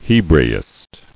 [He·bra·ist]